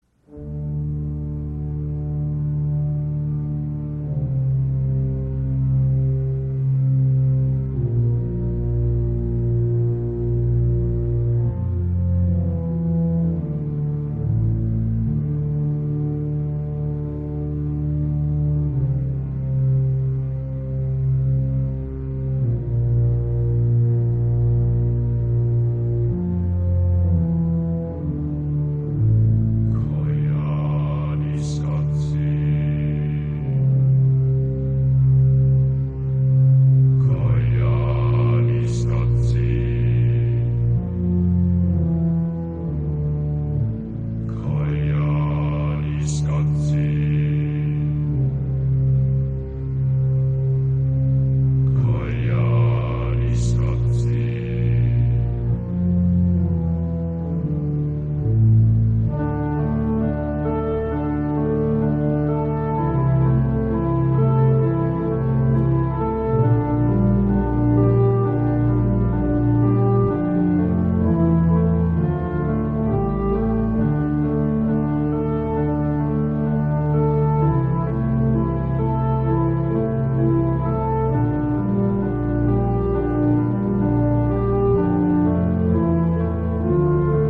magnético minimalismo musical
Y esa música tan hipnótica…